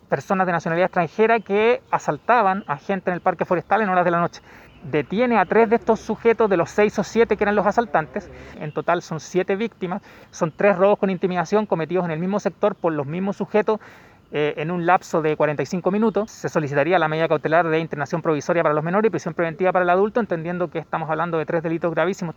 En tanto, el fiscal Felipe Olivari señaló que pedirán la prisión preventiva para el adulto y la internación provisoria para los adolescentes.